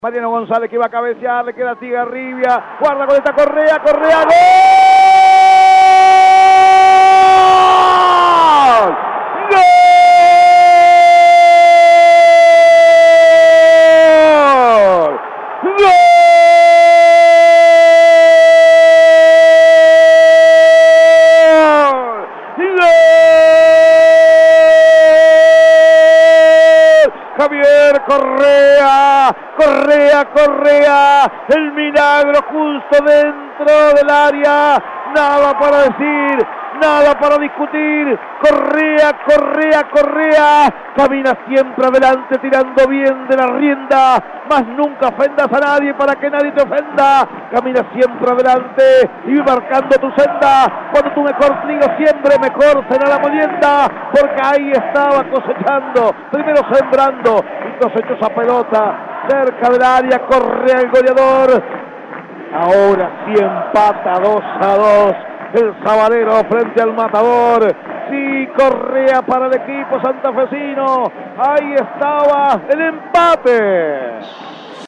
Reviví los goles de Colón con los relatos